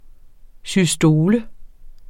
Udtale [ syˈsdoːlə ]